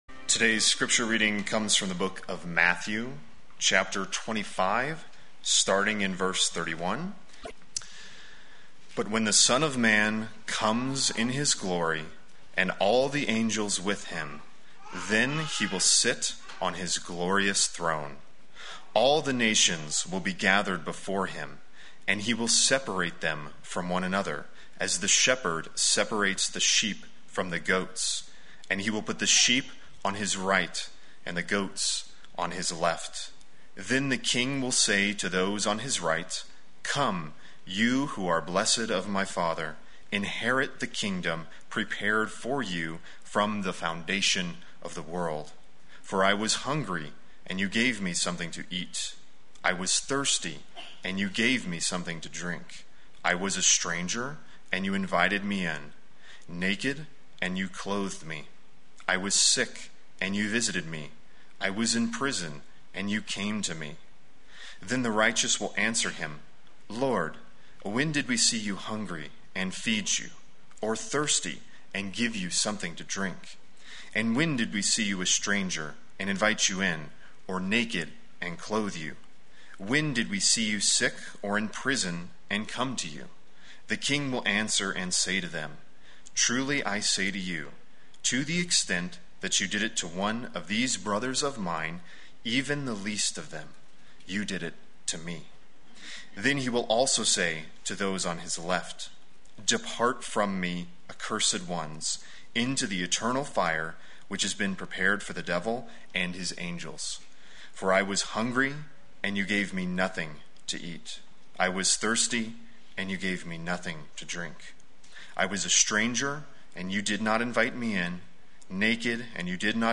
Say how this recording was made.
Welcome the Weak Sunday Worship